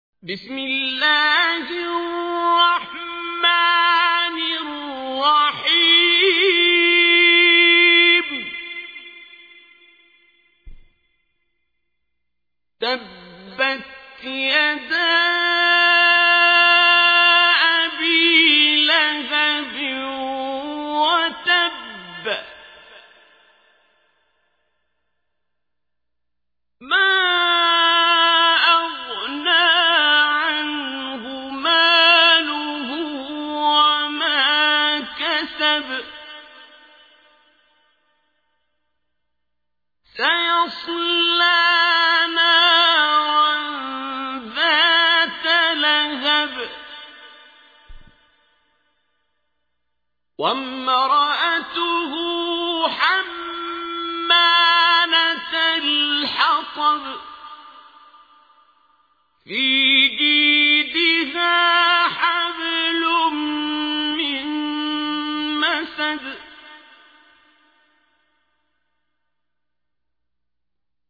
تحميل : 111. سورة المسد / القارئ عبد الباسط عبد الصمد / القرآن الكريم / موقع يا حسين